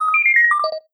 parent-tool-sound-success.wav